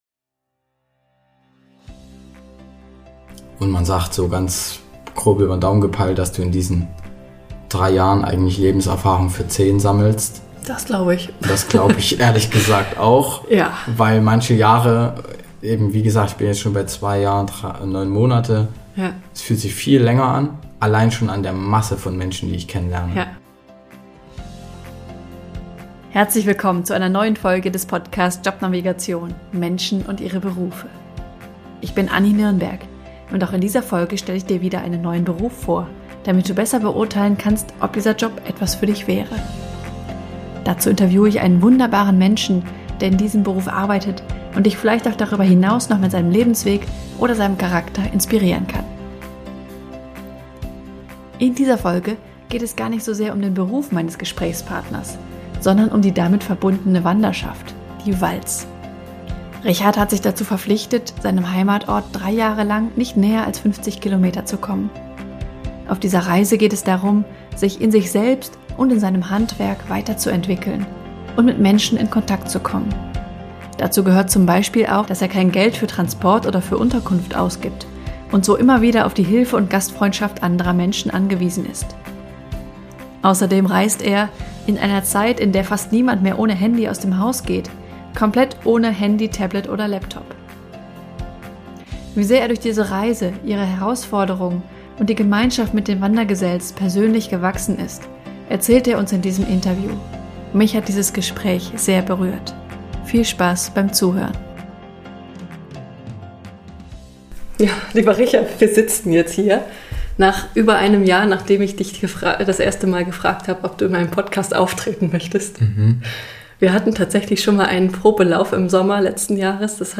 Mich hat dieses Gespräch sehr berührt.